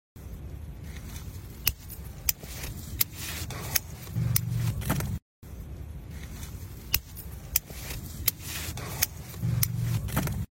Tiếng dùng kéo Thu hoạch rau, củ, quả và ném vào sọt
Thể loại: Tiếng động
Đây là loại sound effect cực kỳ hữu ích cho những ai làm video edit, lồng tiếng, với âm thanh nền mô tả rõ tiếng kéo cắt thực vật, lá cây xào xạc, dao kéo lách cách, tiếng trái cây rơi vào rổ, hay âm thanh hái rau cực chill.
tieng-dung-keo-thu-hoach-rau-cu-qua-va-nem-vao-sot-www_tiengdong_com.mp3